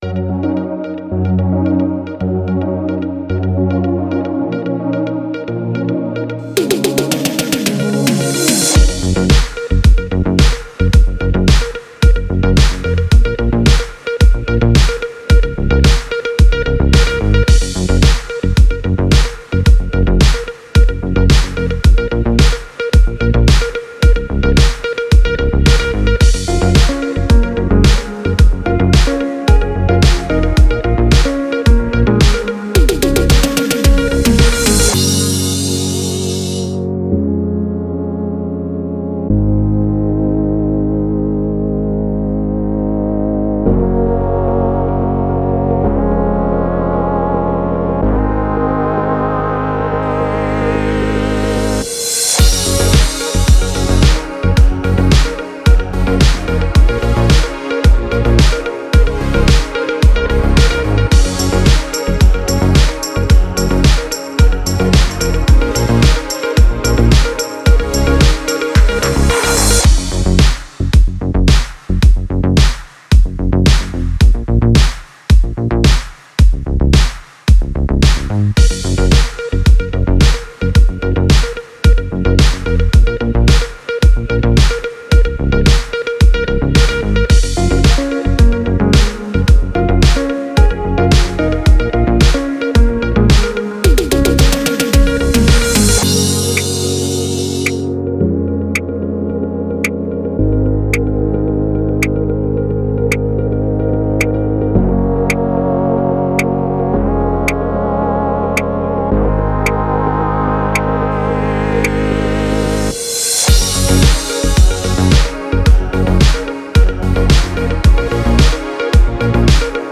Audio para Jingles ou Apresentação Comercial